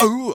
ow.wav